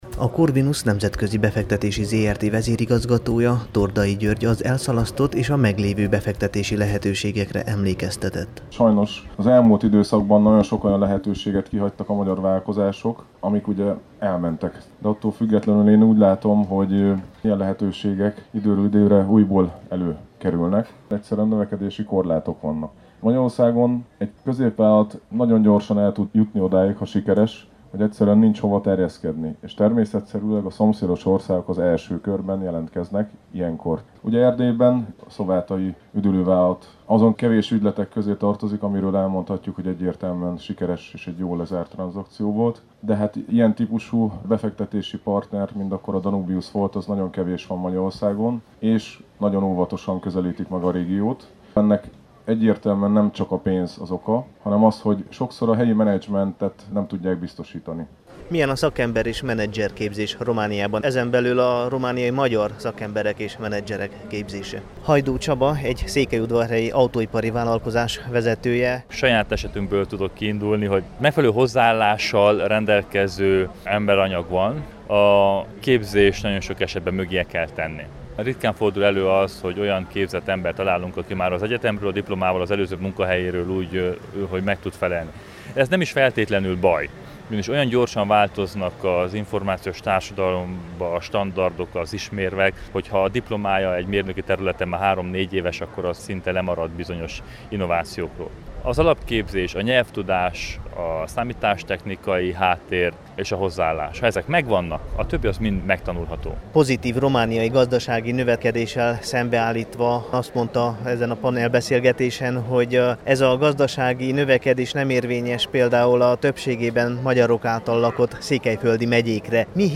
Ezt a 25. Bálványosi Nyári Szabadegyetem és Diáktábor, a Tusványos Wekerle-Mikó-sátrában tartott pénteki beszélgetés résztvevői mondták egybehangzóan.